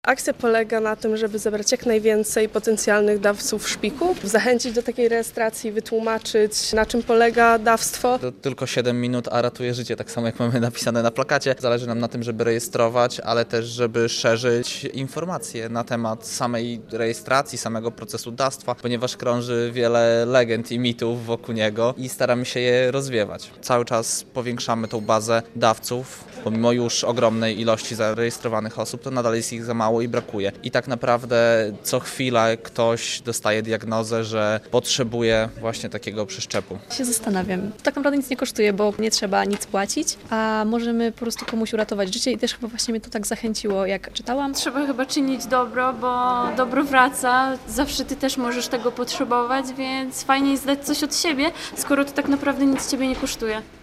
Wystartowała wiosenna edycja akcji Helpers' Generation Fundacji DKMS - relacja